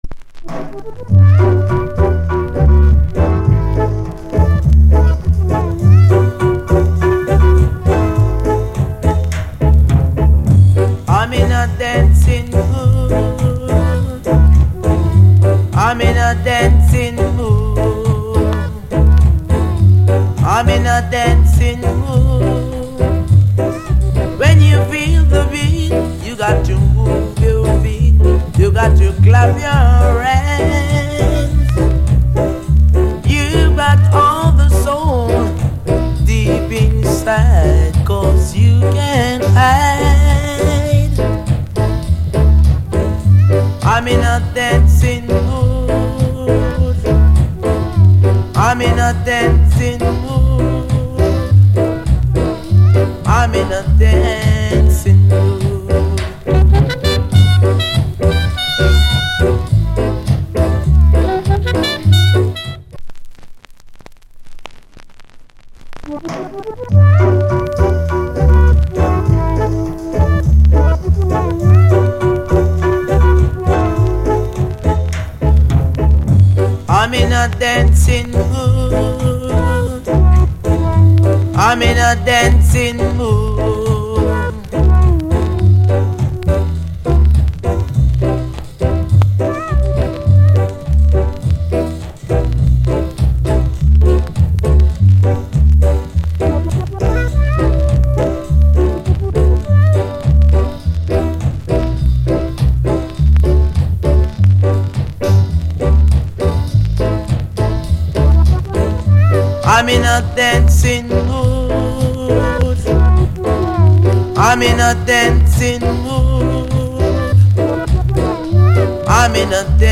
Aシンセのオーバーダブ、少しオリジナルと歌詞違います。